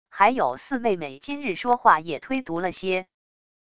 还有四妹妹今日说话也忒毒了些wav音频生成系统WAV Audio Player